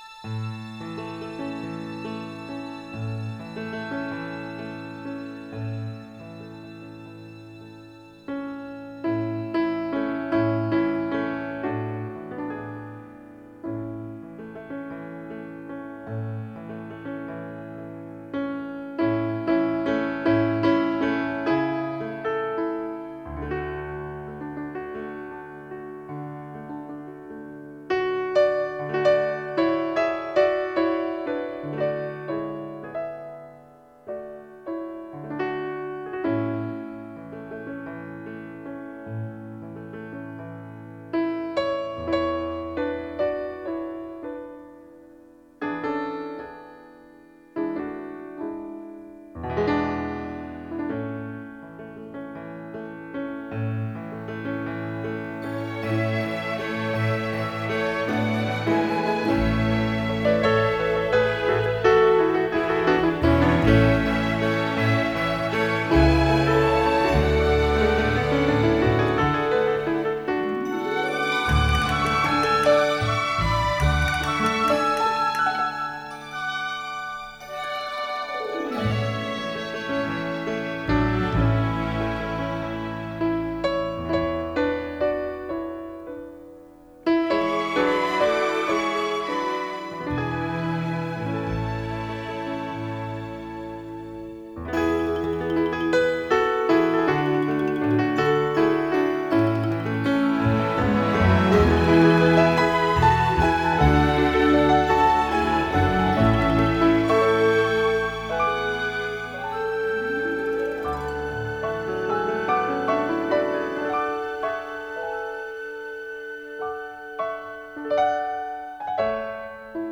Ethereal… a blessing of joy and peace.